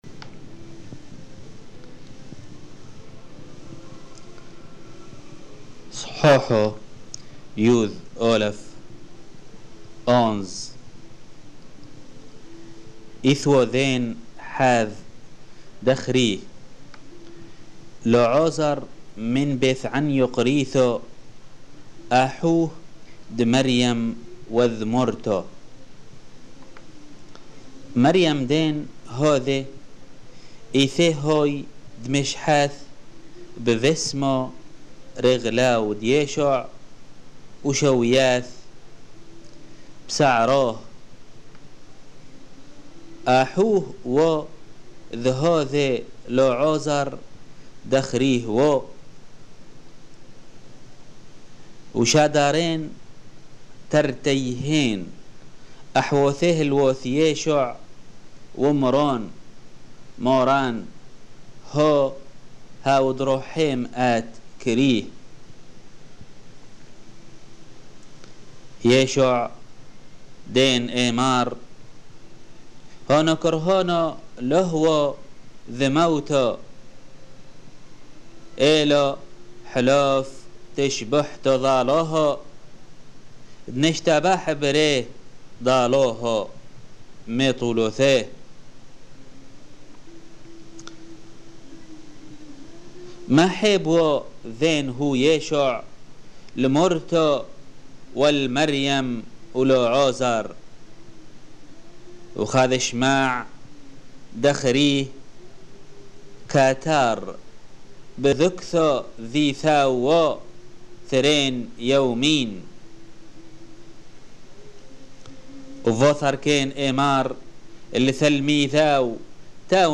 Lecture de l'évangile de Jean, chap. 10-13 en langue syriaque (peshitta)